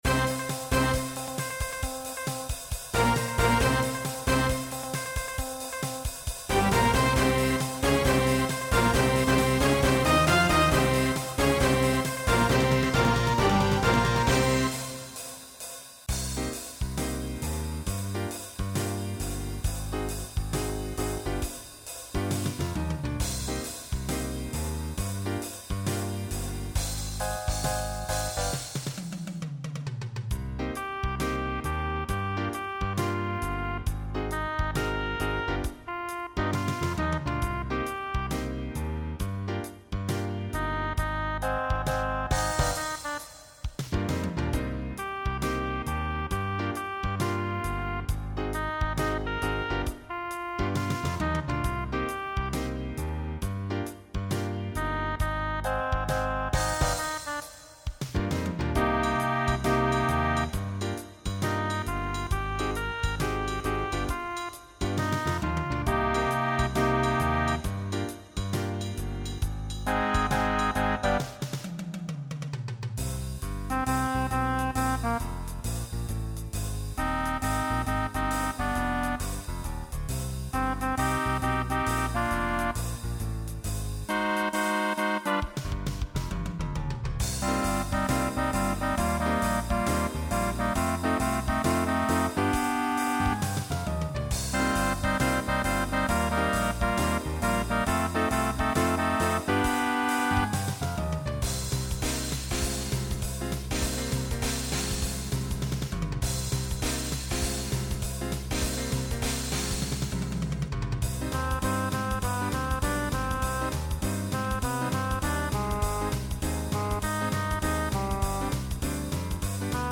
Voicing SATB Instrumental combo Genre Pop/Dance , Swing/Jazz
Mid-tempo